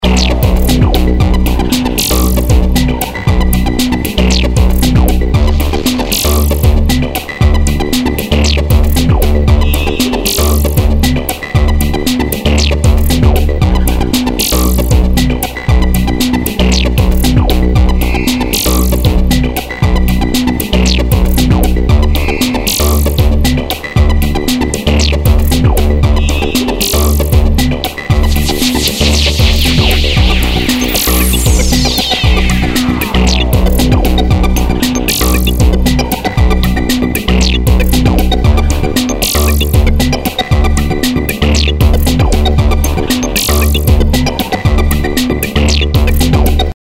Hier sind alle Engines dabei, und es wird deutlich, dass Geist weit mehr kann als „nur“ Drums & Percussion: